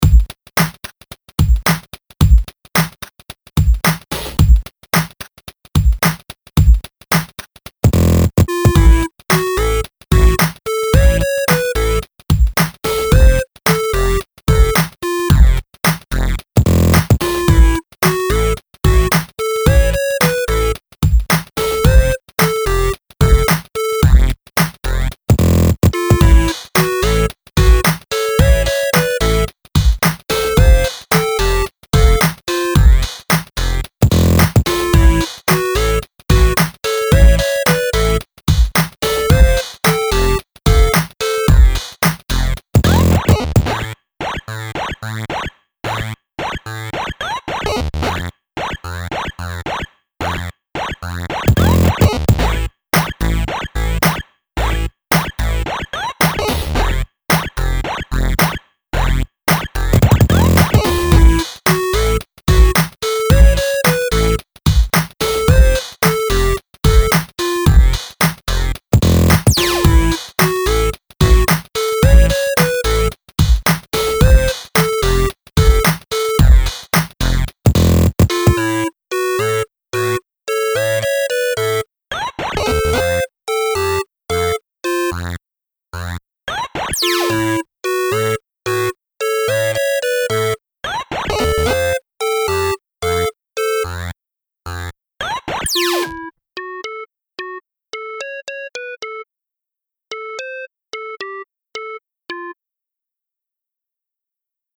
Twisted 8-bit computer game fun.